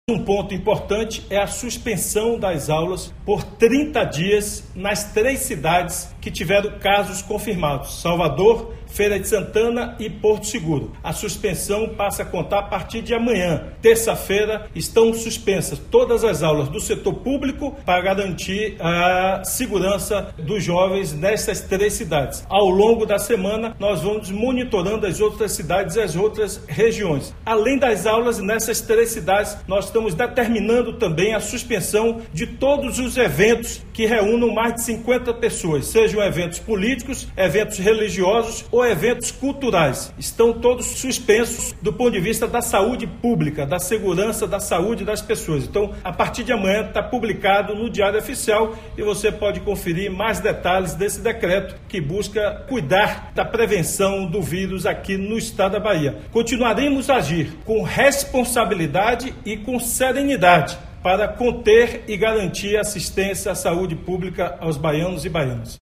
“O objetivo é assegurar a saúde pública, a segurança da saúde das pessoas”, disse Rui Costa em áudio divulgado por sua assessoria.
OUÇA OS ÁUDIOS DO GOVERNADOR RUI COSTA